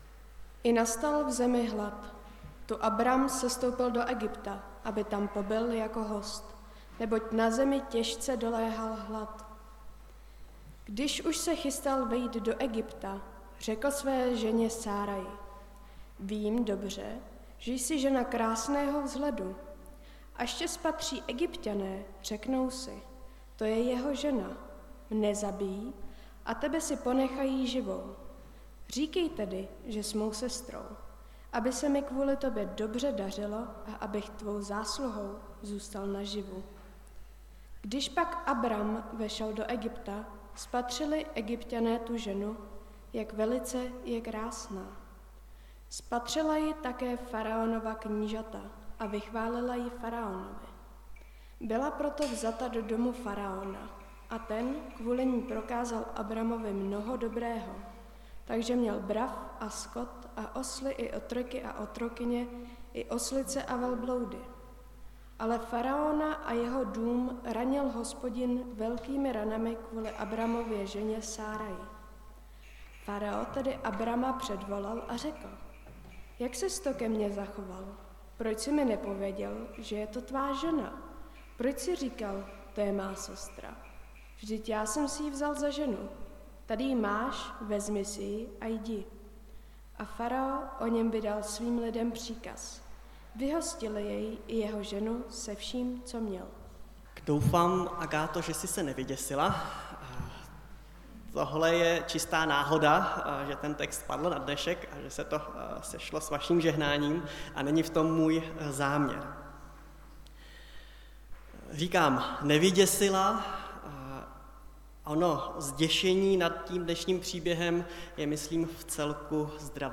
Nedělní kázání – 11.9.2022 Abramův první sestup